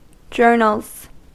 Ääntäminen
Ääntäminen US Haettu sana löytyi näillä lähdekielillä: englanti Journals on sanan journal monikko.